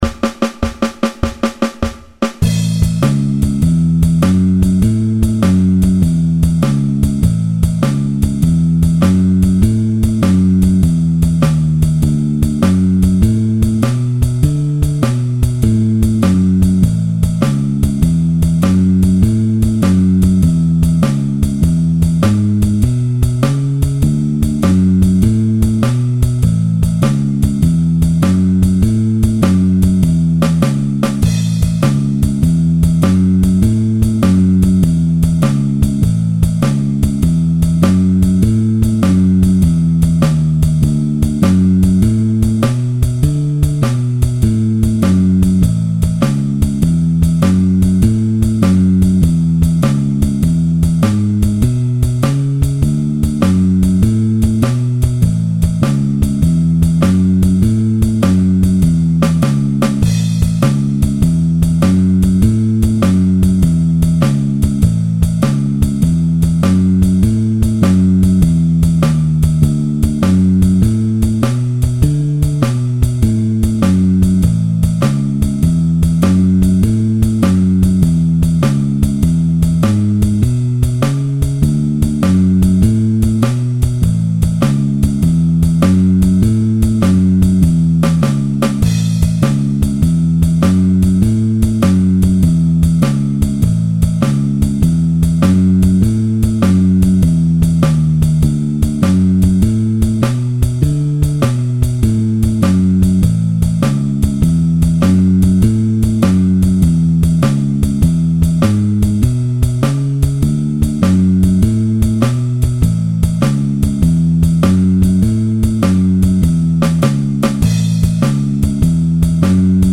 12-bar Blues – Three guitar rhythms combined
I thought I’d simplify the blues down to three distinct rhythm guitar parts – the march, the shuffle and the rhythmic hits –  and show you how to layer them to create a big sound.
backing track here.
blues-march-in-a-long-change.mp3